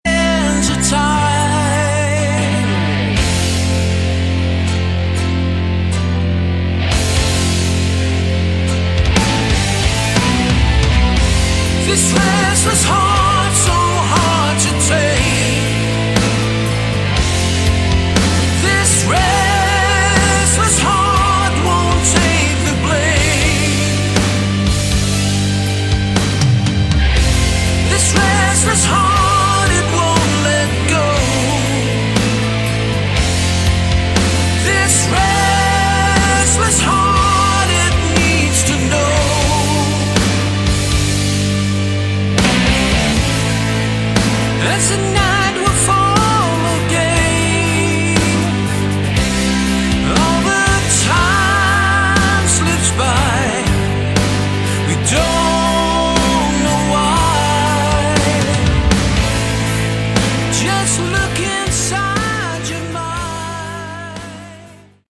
Category: Melodic Rock
guitar
vocals
bass
keyboards
drums